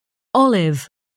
/ˈæp(ə)l/
tip：发音是调用的网易有道词典的api，选的是英音。